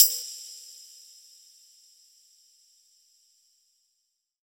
HP133PERC1-L.wav